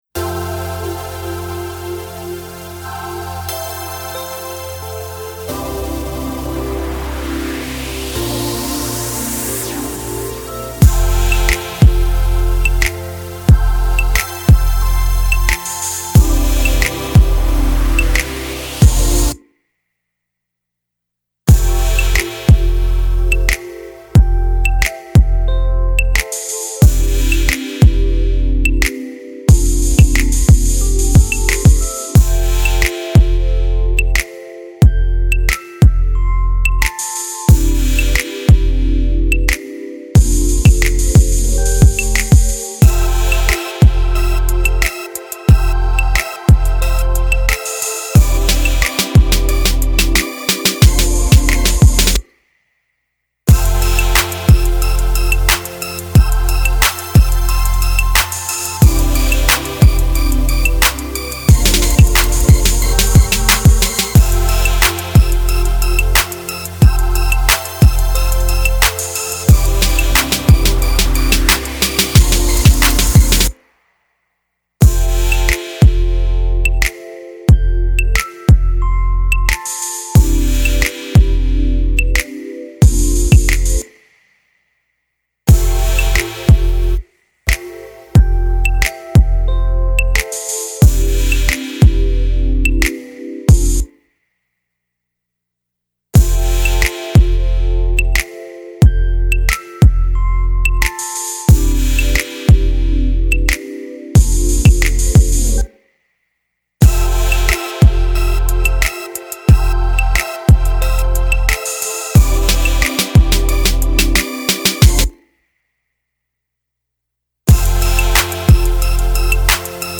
We offer you a complete professional sound kit inspired by Trey Songz, perfect to make beats by your own.
Bass
Claps
Hats
Kicks
Percs
Shakers
Snares